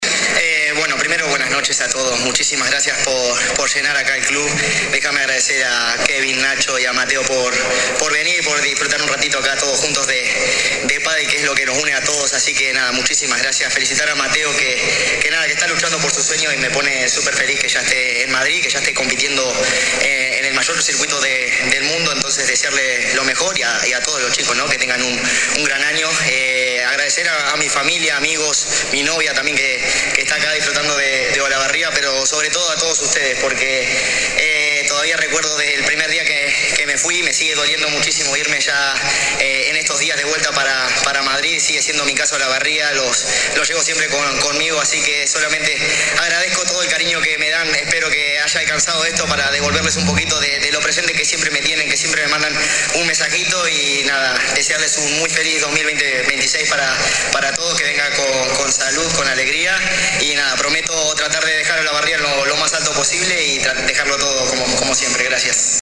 Palabras del destacado deportista olavarriense tras la exhibición realizada anoche ante enorme concurrencia.
AUDIO DE «CHINGO» ANTE LA CONCURRENCIA